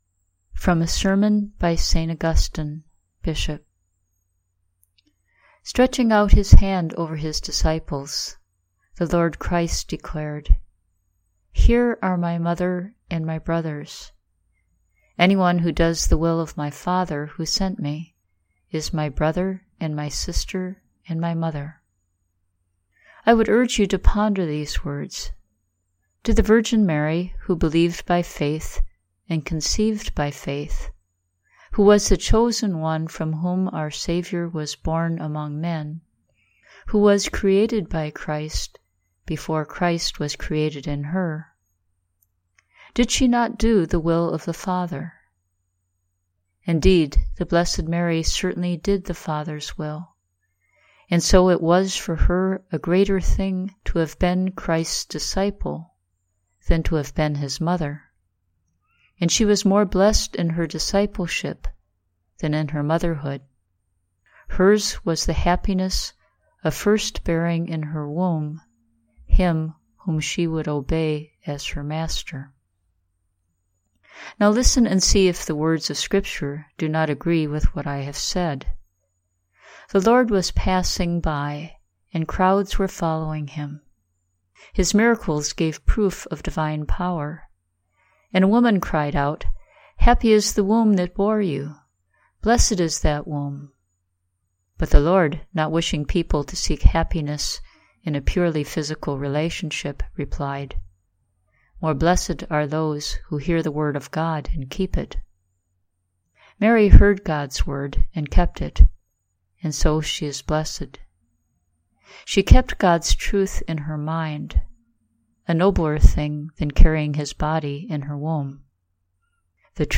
Text: from a sermon by St. Augustine, Bishop